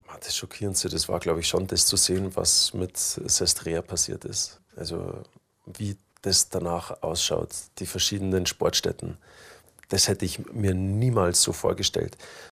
Felix Neureuther zu Turin 2006, wo er als Sportler teilgenommen hat: